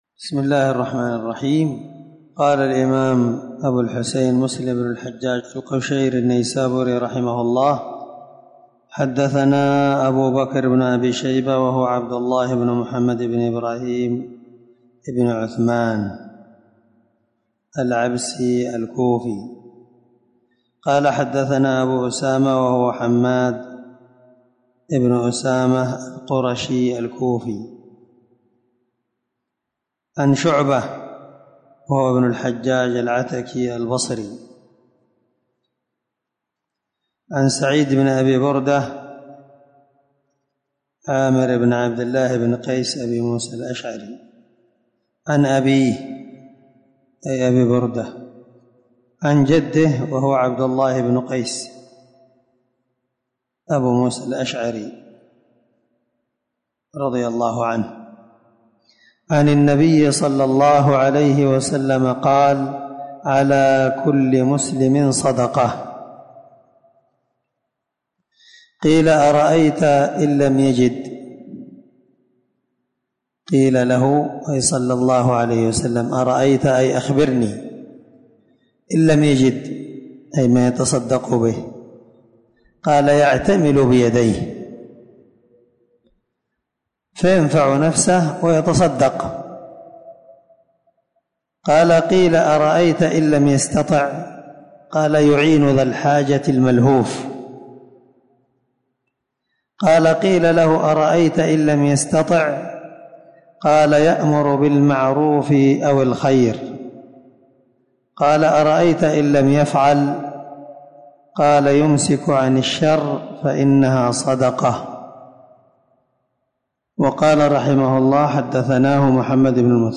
615الدرس 23من شرح كتاب الزكاة حديث رقم(1008-1009) من صحيح مسلم
دار الحديث- المَحاوِلة- الصبيحة.